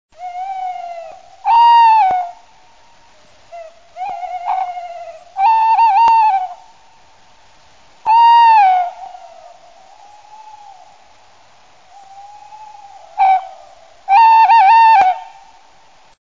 Canto del cárabo común
canto-carabo.mp3